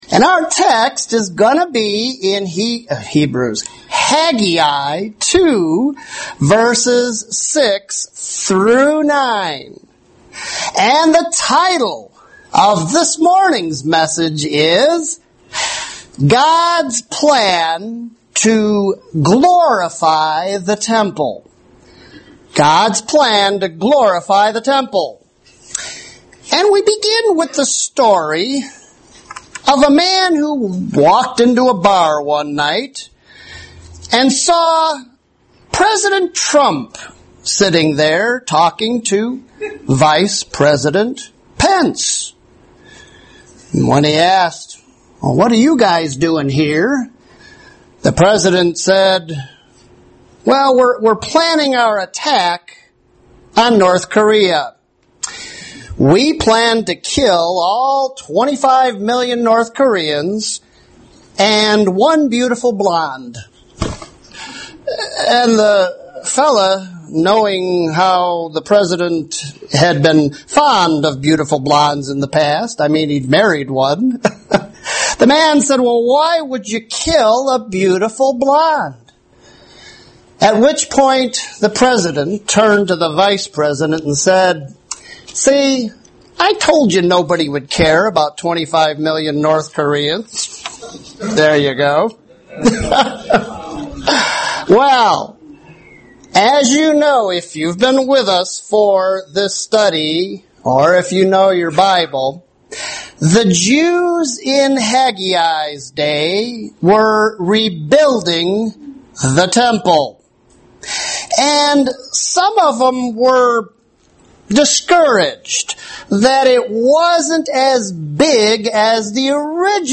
You're listening to Lesson 5 from the sermon series "Haggai"